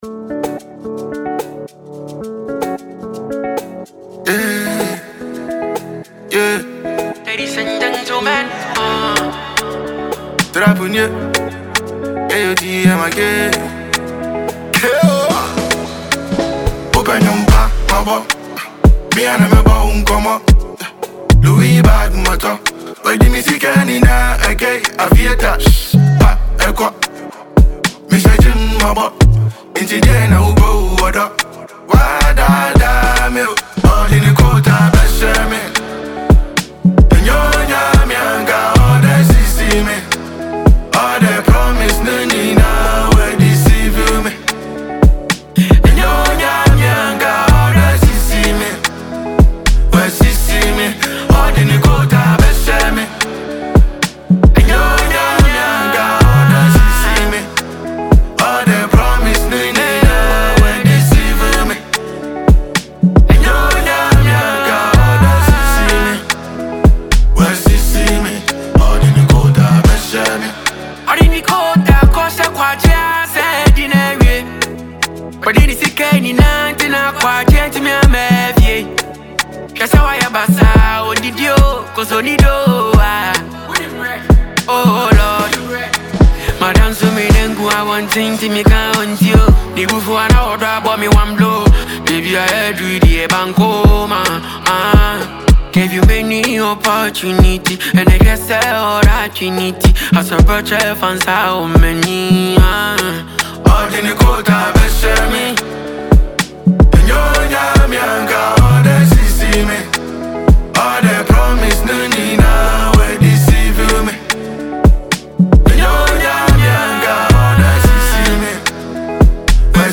a Ghanaian trapper